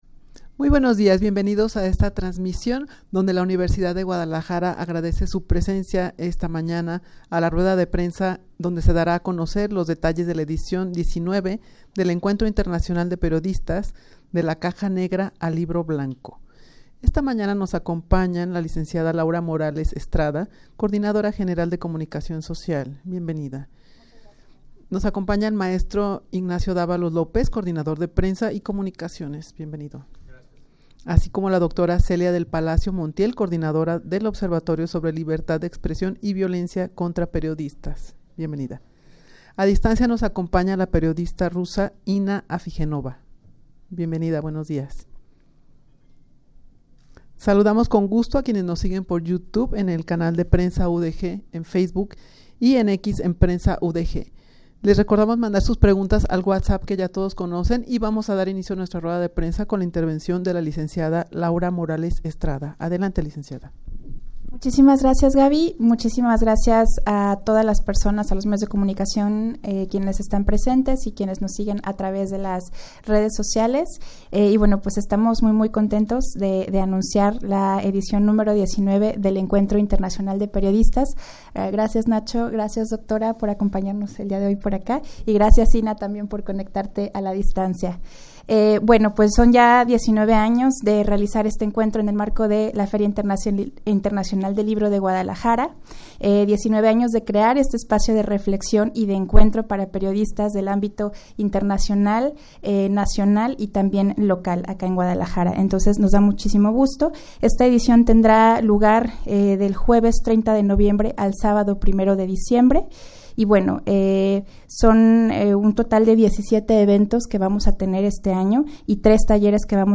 Audio de la Rueda de Prensa
rueda-de-prensa-para-dar-a-conocer-los-detalles-de-la-edicion-xix-del-encuentro-internacional-de-periodistas.mp3